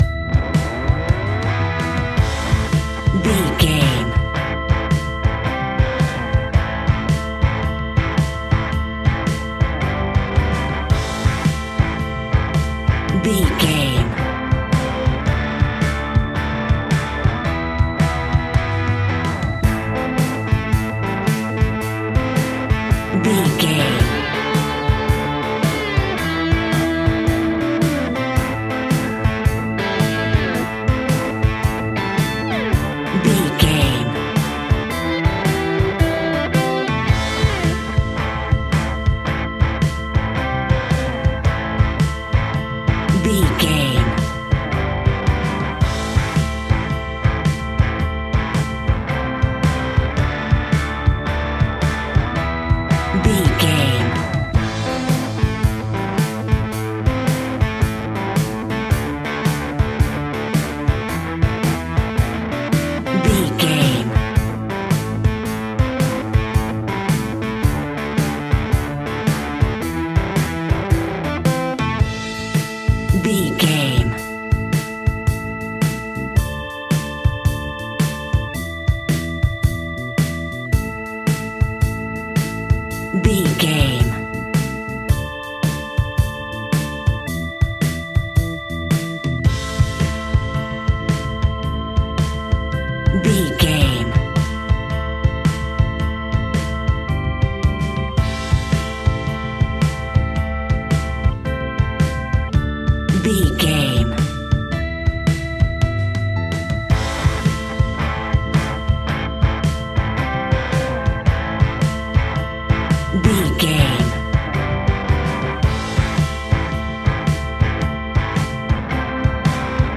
Ionian/Major
hard rock
heavy rock
blues rock
distortion
instrumentals